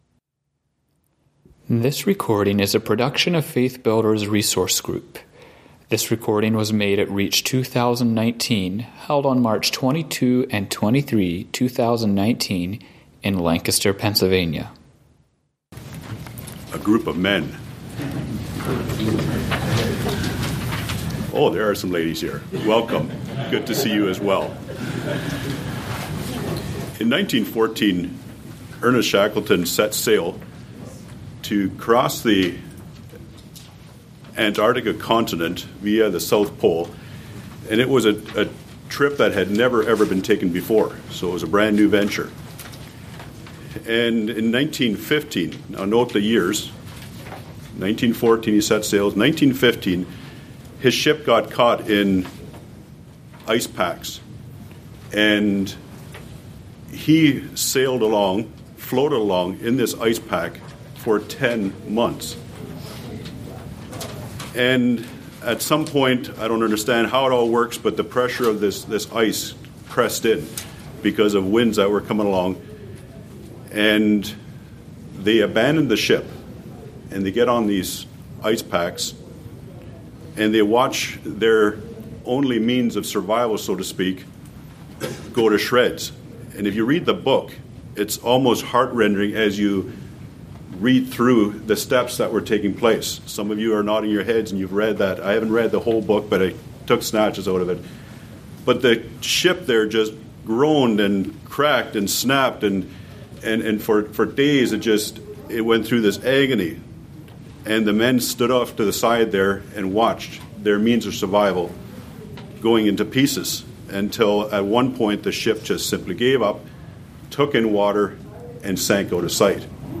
Home » Lectures » Leadership When Holes Have Sprung in Our Ship